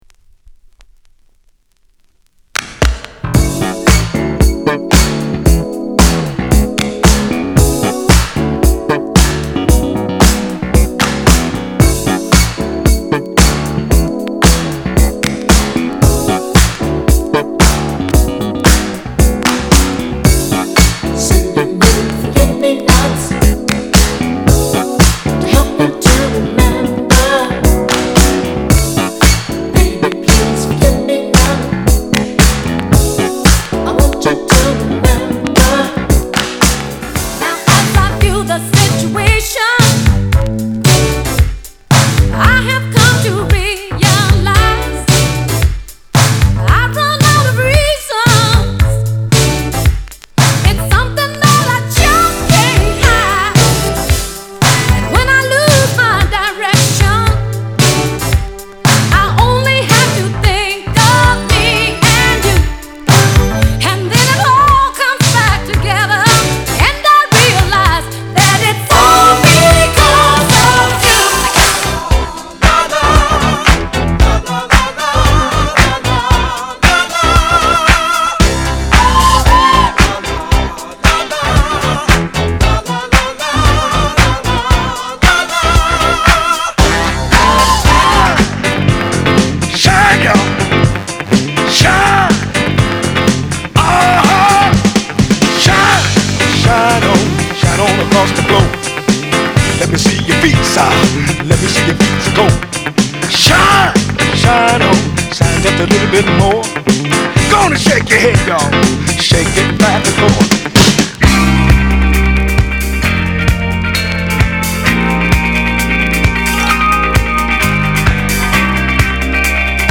category R&B & Soul